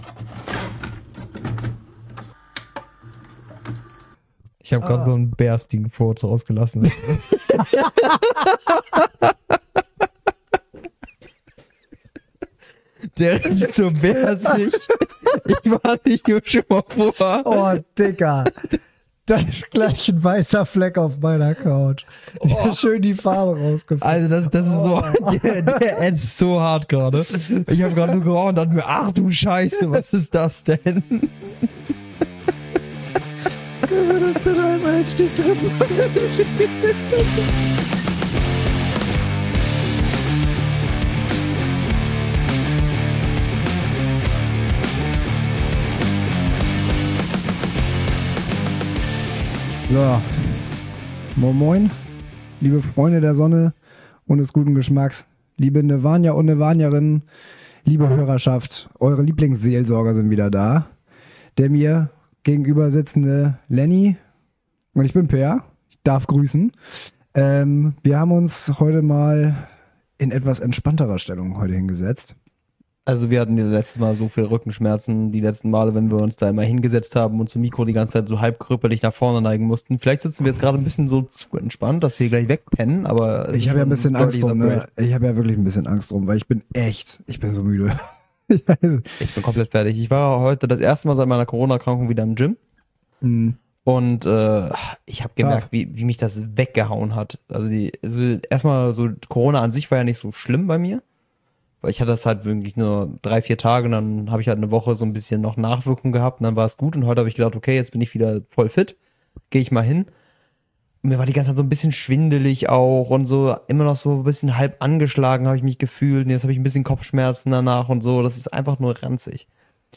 Mit der letzten Folge dieses Jahres blicken wir in einem entspannten Gespräch auf komische Massage-Erfahrungen und nostalgische Kindheitsmomente zurück und widmen uns dem Thema Prokrastination.